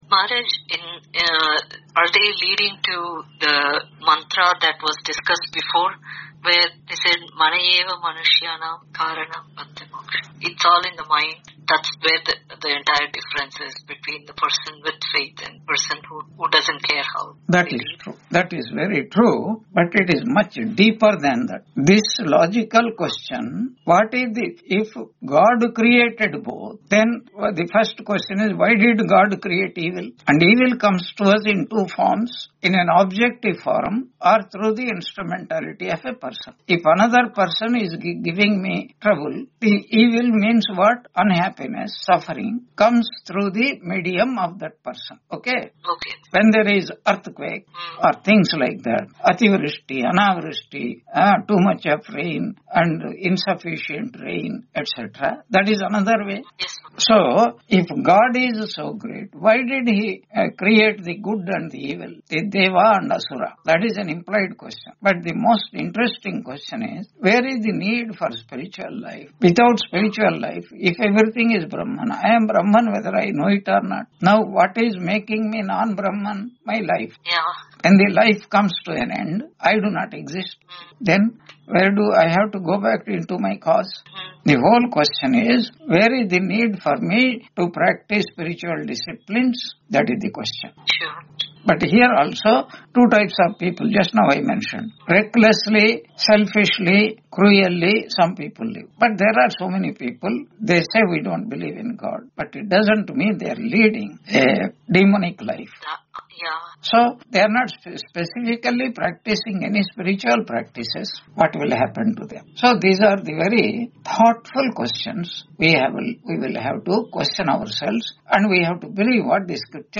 Taittiriya Upanishad Lecture 81 Ch2 6.1-2 on 03 December 2025 Q&A - Wiki Vedanta